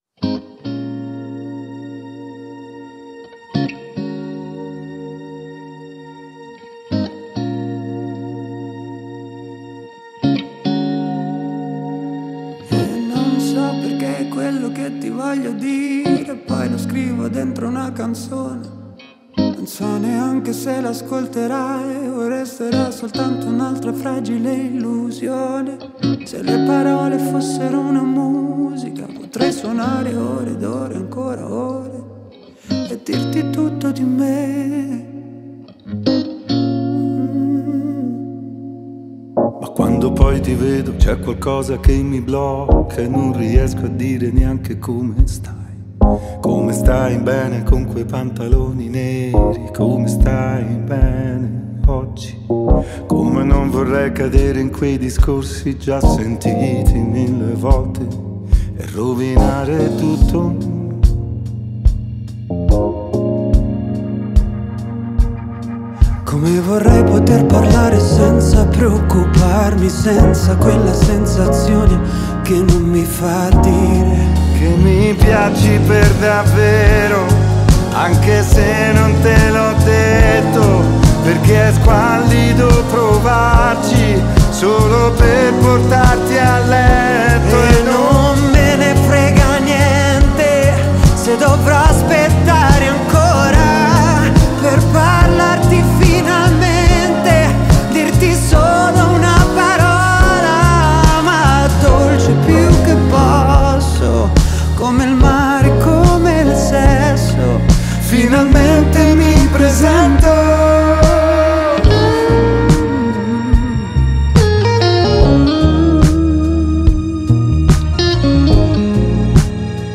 Dalle novità di Feat.Pop alla musica ai tempi delle piattaforme, Alex Britti ci ha parlato anche dell’evoluzione della black music e del suo ormai irrinunciabile bisogno di autenticità. Ascolta l’intervista